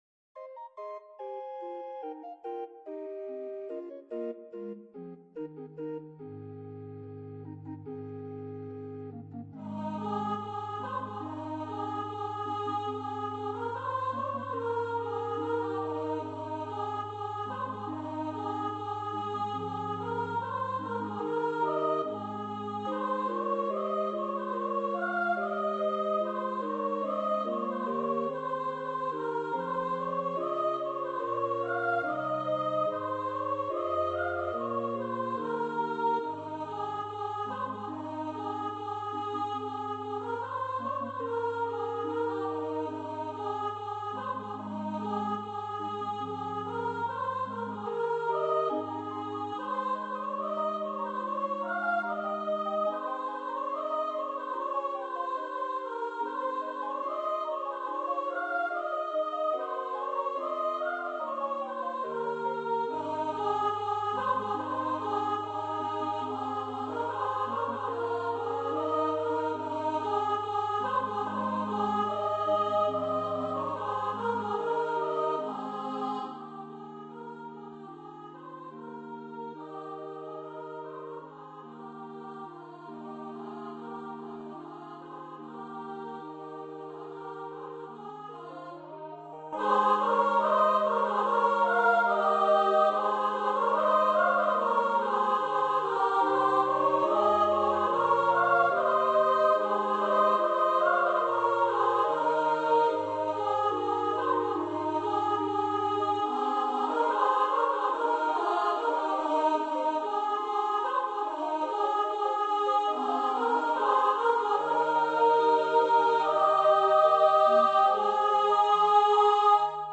for upper voice choir and organ or piano